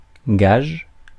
Ääntäminen
Tuntematon aksentti: IPA: /ɡaʒ/